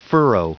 Prononciation du mot furrow en anglais (fichier audio)
Prononciation du mot : furrow